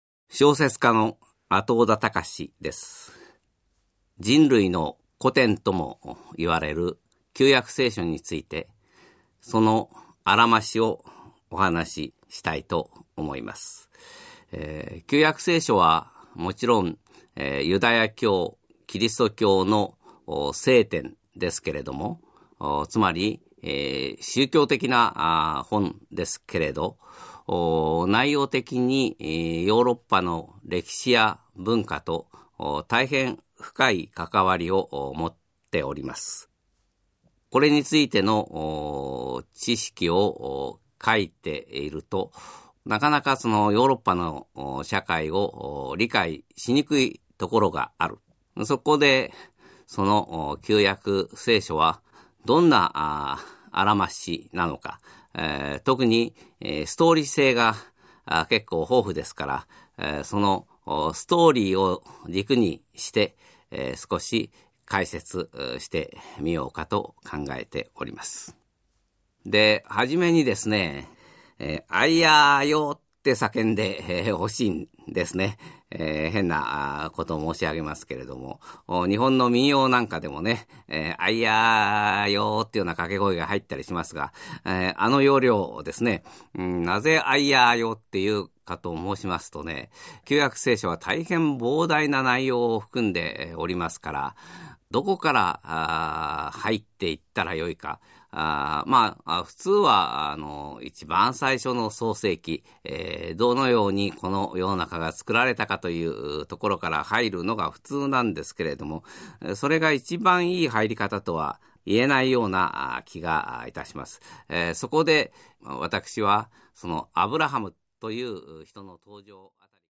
[オーディオブック] 聴く歴史・海外『旧約聖書を知っていますか？【前半】』
興味深い逸話が凝縮した、語りで聴く旧約聖書のダイジェストです。
作家 阿刀田高